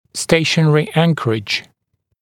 [‘steɪʃənərɪ ‘æŋkərɪʤ][‘стэйшэнэри ‘энкэридж]стационарная опора, неподвижная опора, абсолютная опора